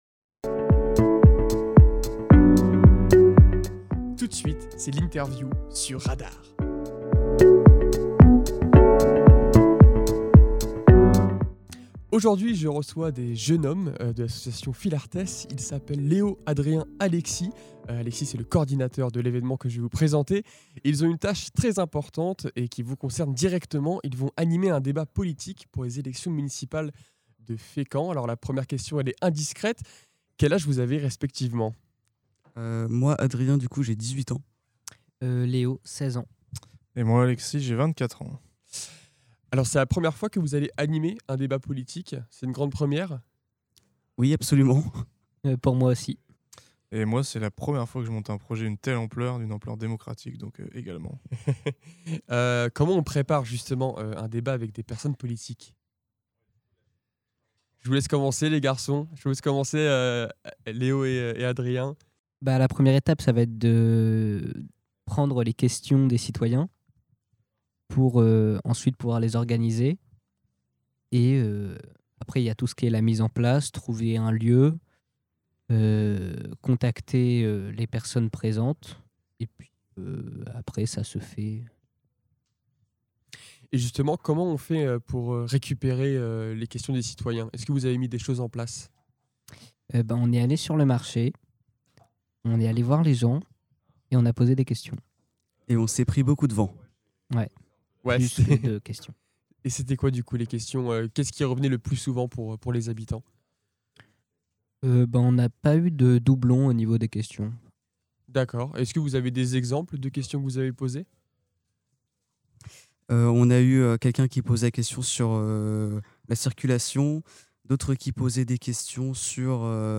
Actu, sport, vie associative et bien plus encore : ici, vous retrouverez toutes les interviews produites par RADAR 🎙